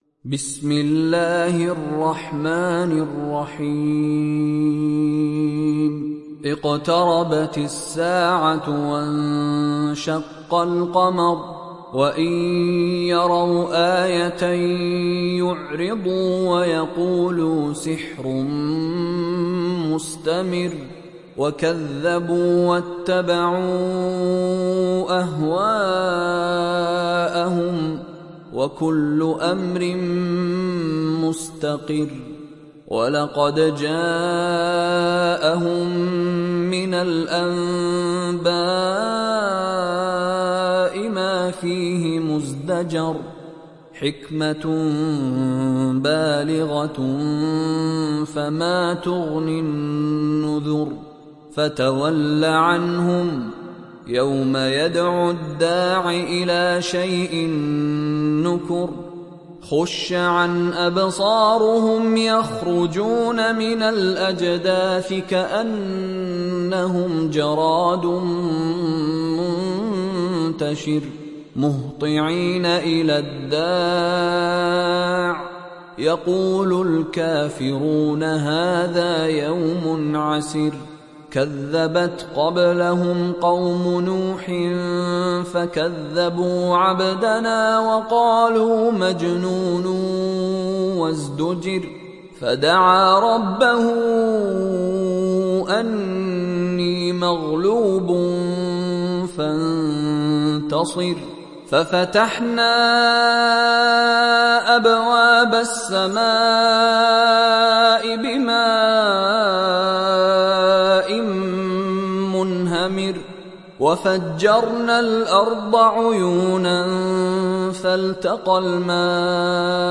دانلود سوره القمر mp3 مشاري راشد العفاسي روایت حفص از عاصم, قرآن را دانلود کنید و گوش کن mp3 ، لینک مستقیم کامل